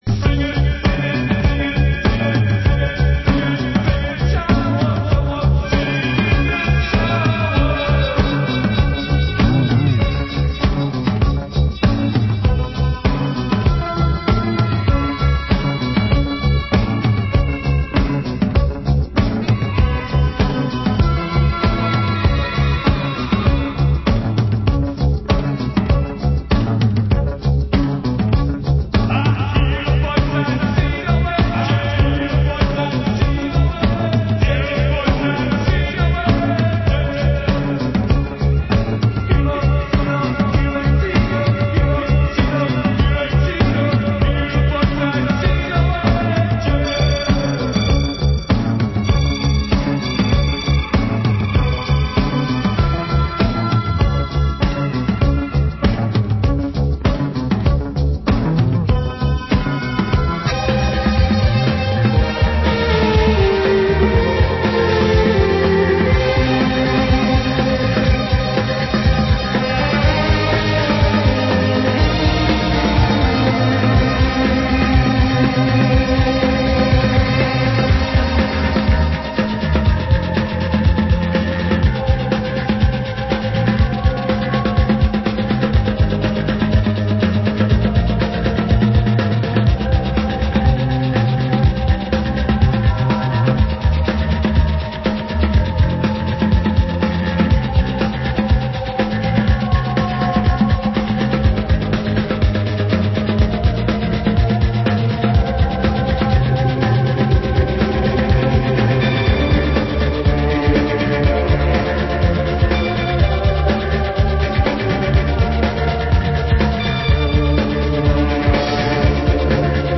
Genre: Balearic